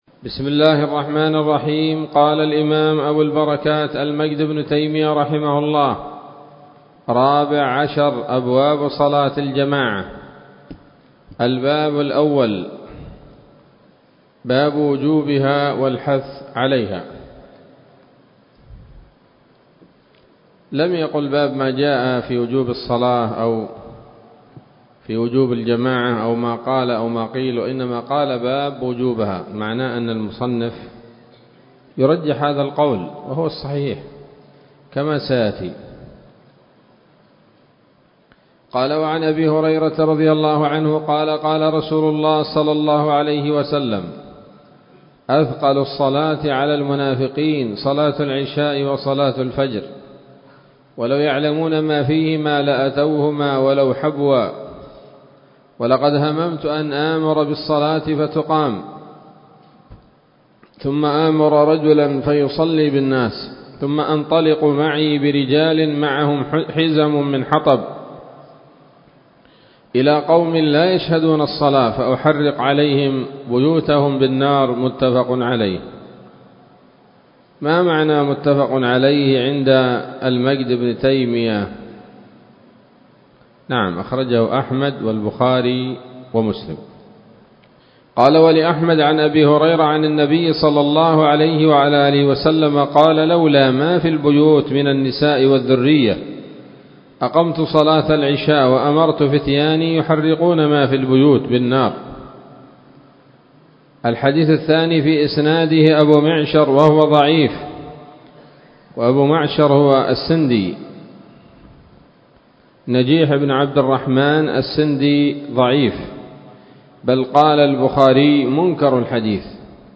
الدرس الأول من ‌‌‌‌أبواب صلاة الجماعة من نيل الأوطار